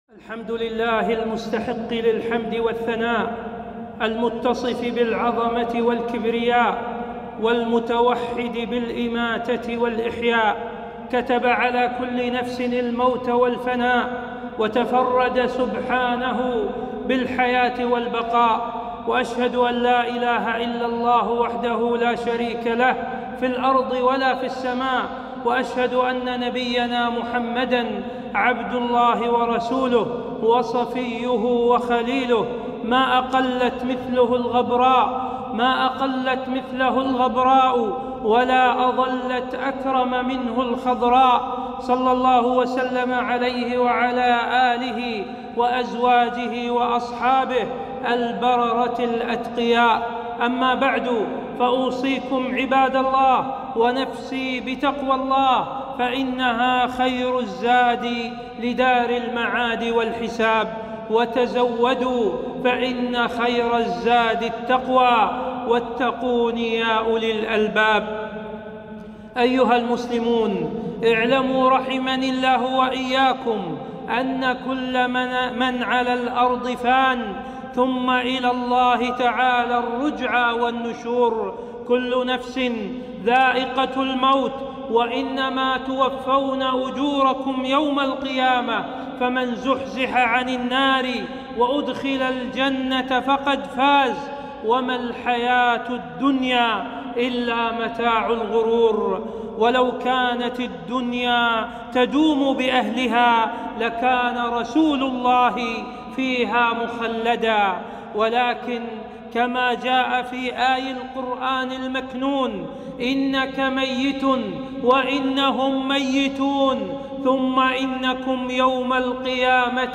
خطبة - قائد العمل الخيري